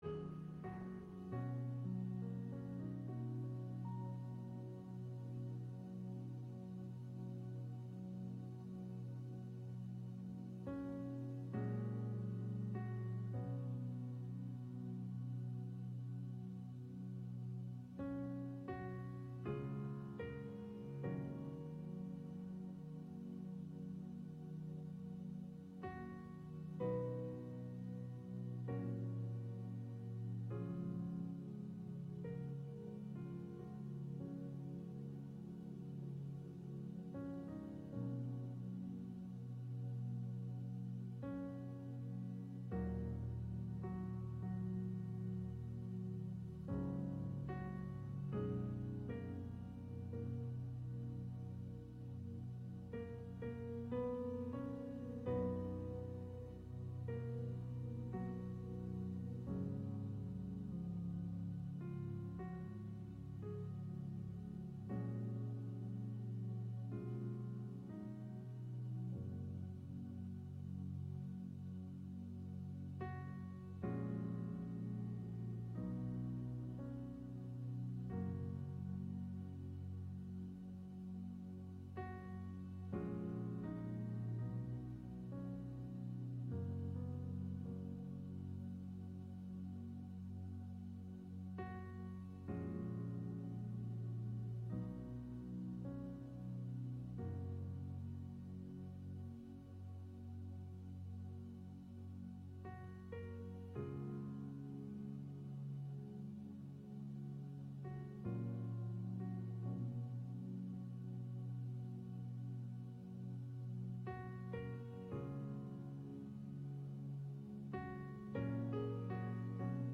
Service Morning Worship
August 23 Worship Audio – Full Service August 23 Sermon Audio Bible References Luke 7:1